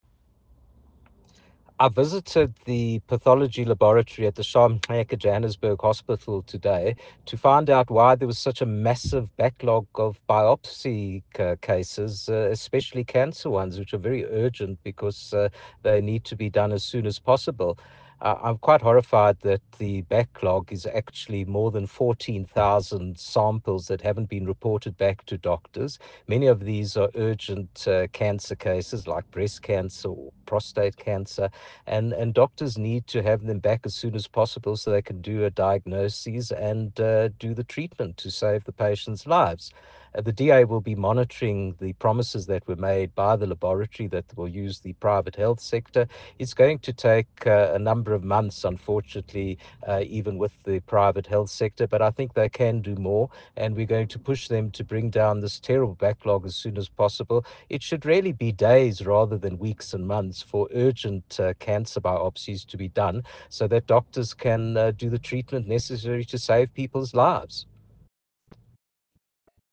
soundbite by Dr Jack Bloom MPL.